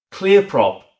Boost your immersion with this lovingly crafted "Clear prop " audio file
So real you'd swear there was a british person in your home.